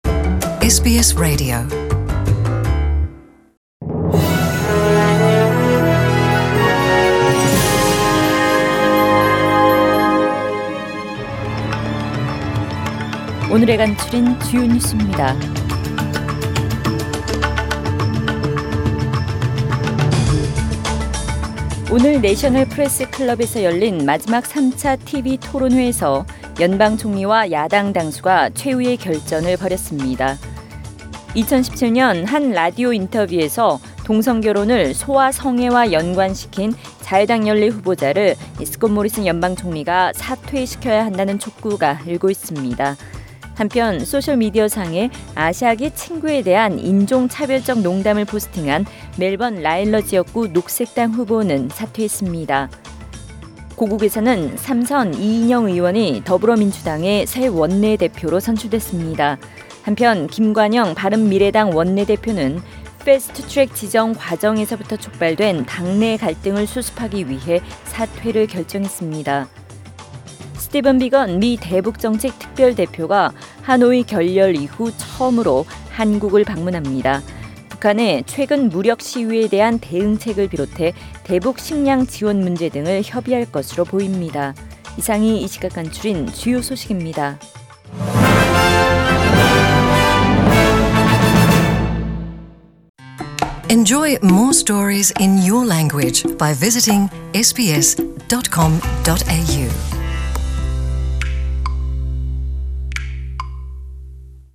SBS 한국어 뉴스 간추린 주요 소식 – 5월 8일 수요일
2019년 5월 8일 수요일 저녁의 SBS Radio 한국어 뉴스 간추린 주요 소식을 팟 캐스트를 통해 접하시기 바랍니다.